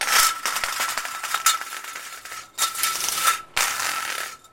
SF X 窗户 " 窗帘噪音
描述：我在'luxaflex'窗帘上滑动我的手指甲。
Tag: 百叶窗 指甲 指甲 luxaflex 金属 钉子 窗格 塑料 加固纹理 纹理 充满活力 窗口 windowblind WindowBlinds的